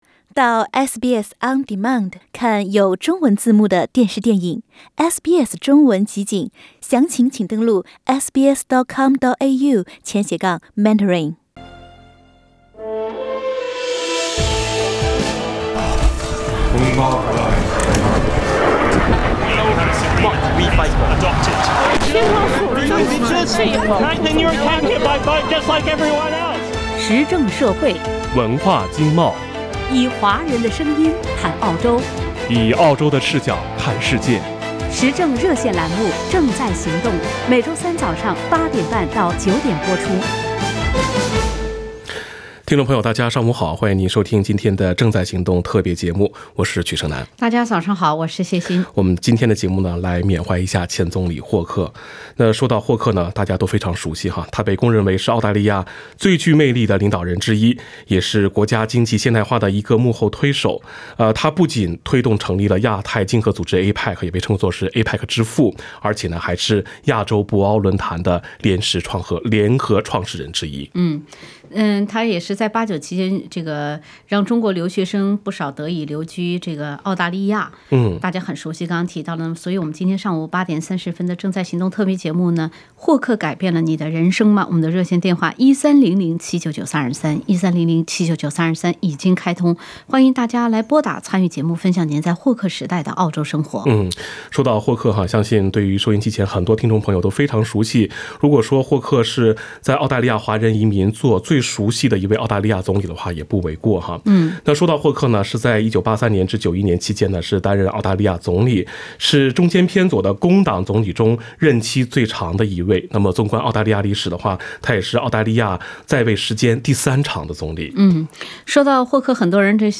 本期节目《你的人生因霍克而改变了吗？》，普通话听众分享早年移民故事，向已故总理鲍勃·霍克致敬。